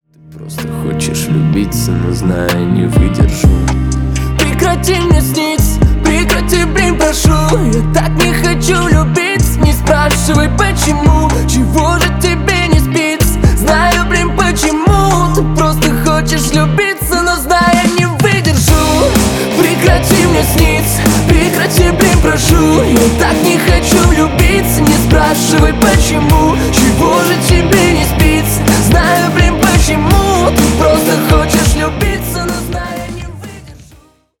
бесплатный рингтон в виде самого яркого фрагмента из песни
Поп Музыка
грустные